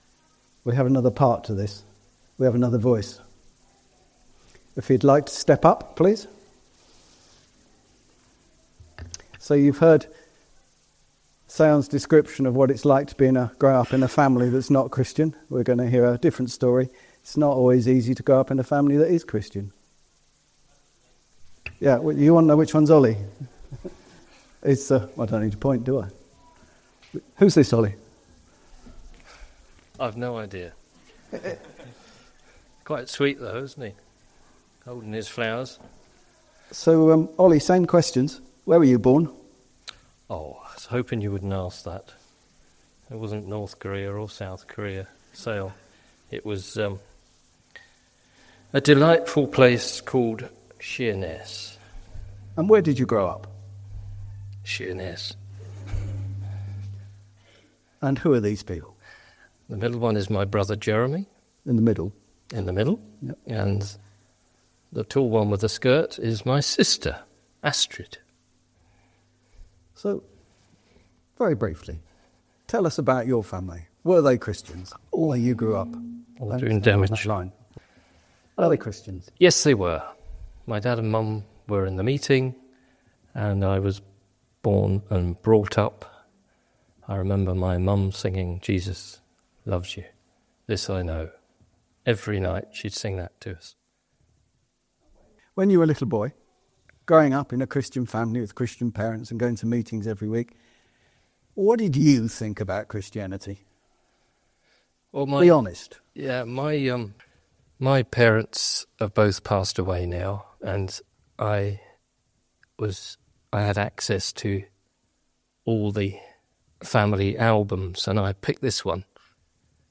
interview recorded at Refresh 2025